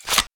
knife_unsheath.ogg